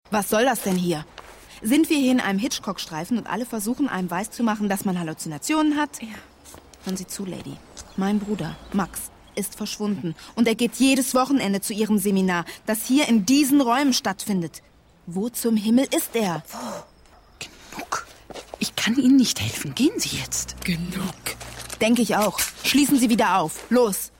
Sprecherin für Hörspiel/ Synchron Stimmfarbe: jung, aufgeweckt,lebendig und sinnlich
Sprechprobe: Sonstiges (Muttersprache):